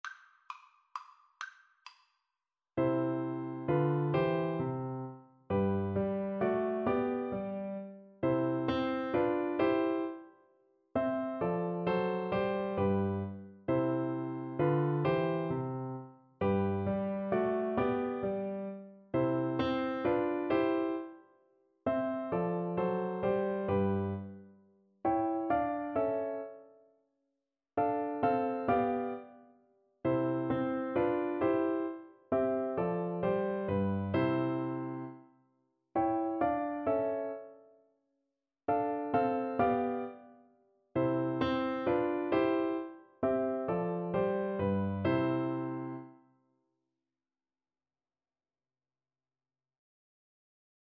• Unlimited playalong tracks
3/4 (View more 3/4 Music)
= 132 Allegro (View more music marked Allegro)
Classical (View more Classical Flute Music)